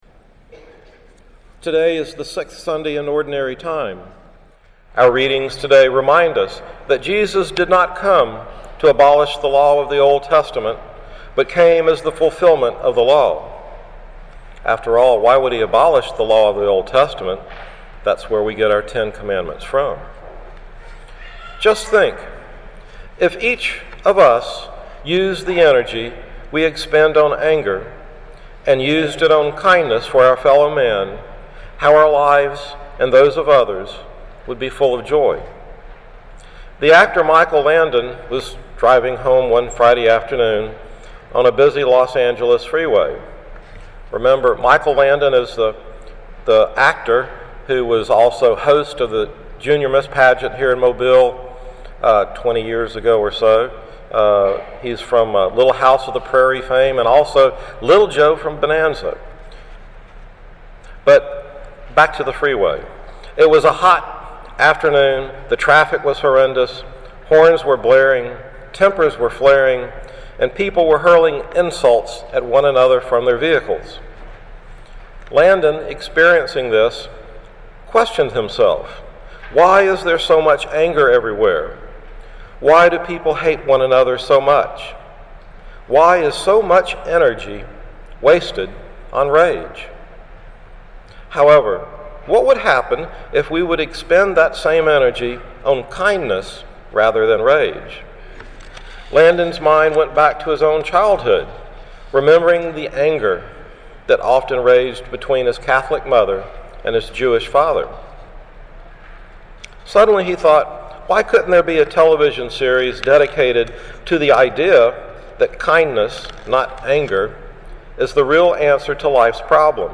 Homily about Anger and Kindness